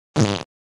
Fart.mp3